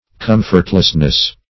Com"fort*less*ly, adv. -- Com"fort*less*ness, n.